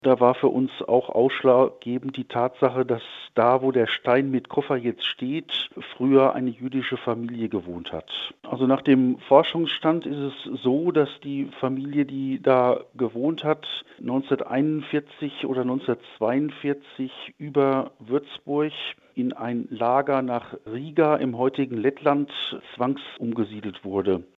Zur Erinnerung an die deportierten jüdischen Mitbürger wurde jetzt ein Koffer in Willmars aufgestellt. Bürgermeister Reimund Voß: